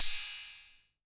808CY_8_Tape.wav